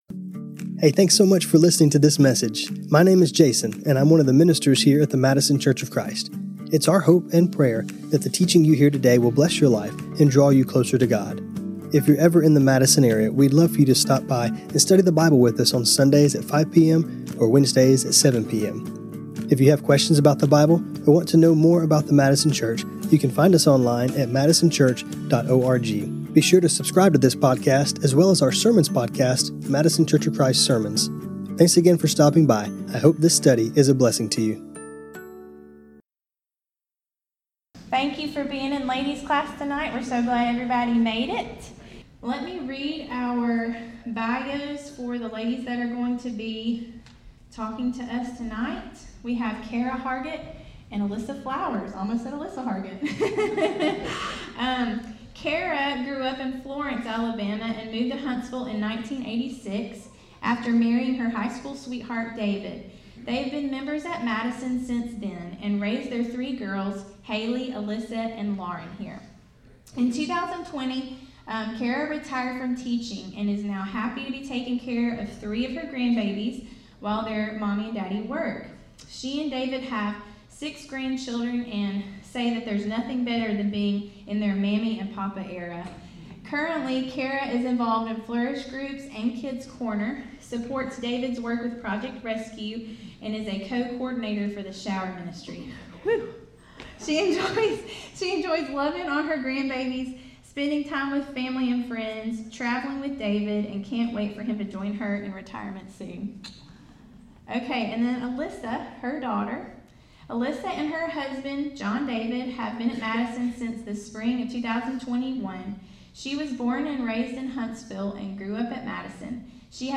When we go through difficult times and even wonderful times, God has given us something that is constant, His word. In this class, we will hear from some of our own sisters as they share the scriptures that have carried them through different life circumstances both good and bad.